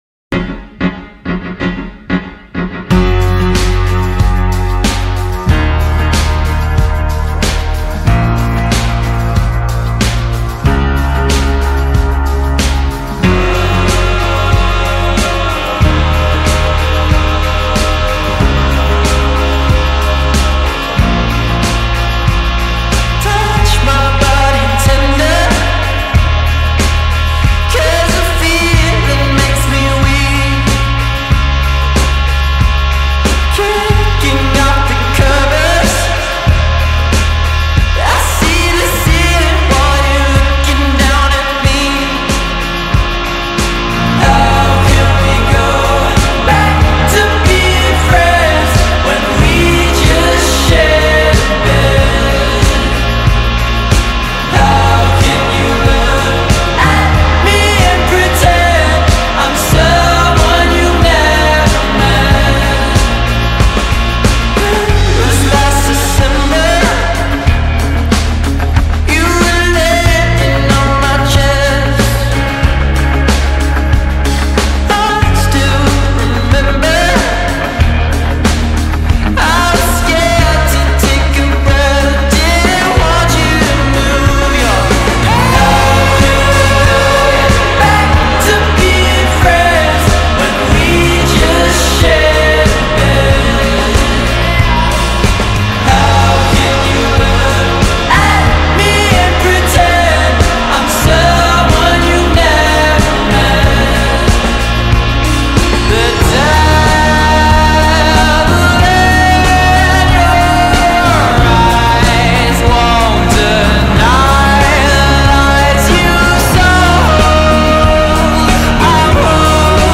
آهنگی غم انگیزه